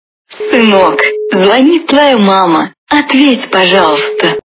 » Звуки » Люди фразы » Звонок от мамы - Сынок звонит твоя мама, ответь, пожалуйста
При прослушивании Звонок от мамы - Сынок звонит твоя мама, ответь, пожалуйста качество понижено и присутствуют гудки.